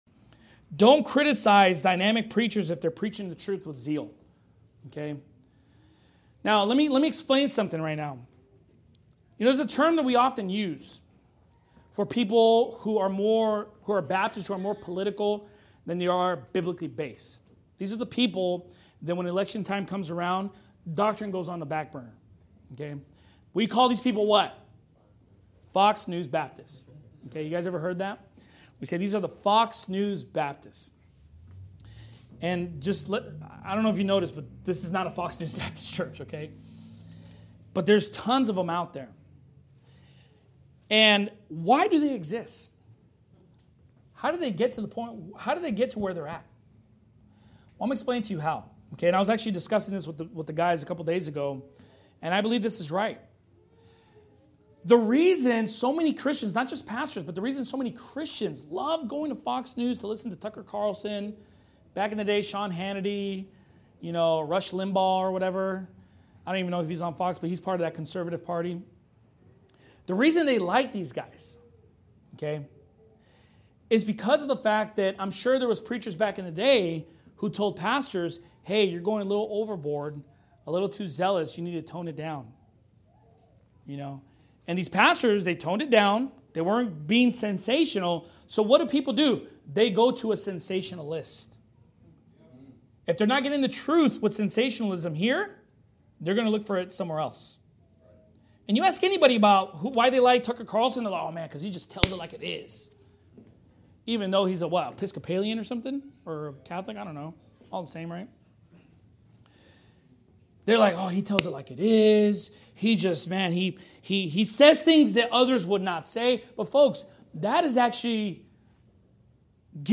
Sermon Clips